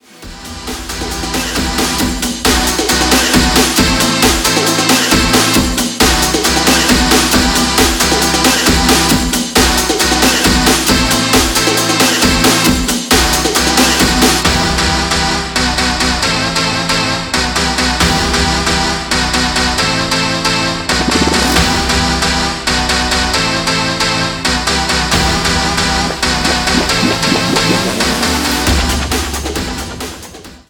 • Качество: 320, Stereo
Стиль: dance & edm.